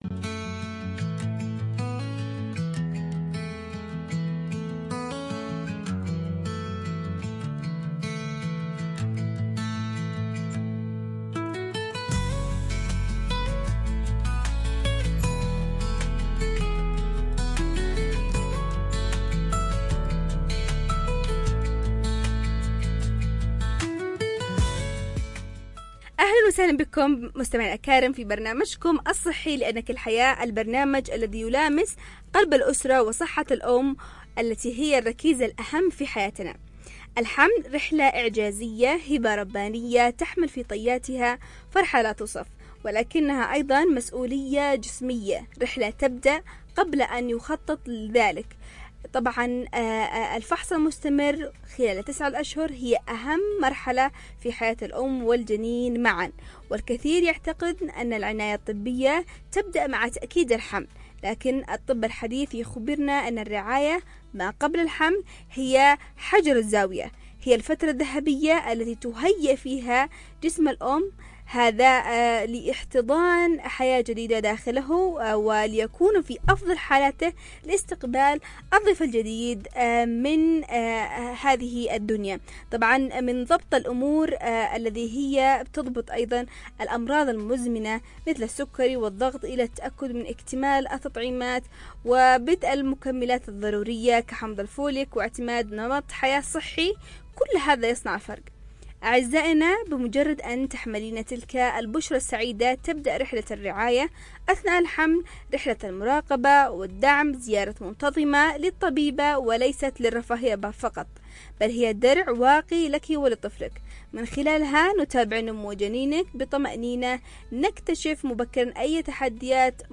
ضمن نقاش توعوي حول أهمية الرعاية الطبية قبل الحمل
عبر أثير إذاعة رمز